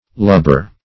Lubber \Lub"ber\, n. [Cf. dial. Sw. lubber. See Looby, Lob.]